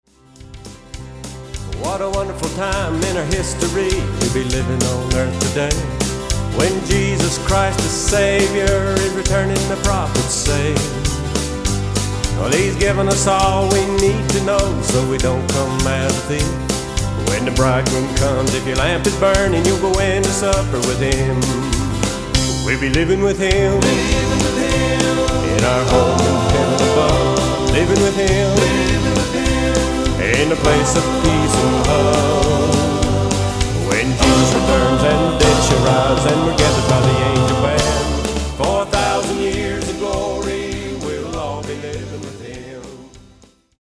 Autoharp
Drums, Lead and Harmony Vocals
Guitar
Bass
Keyboards
Rhythm guitar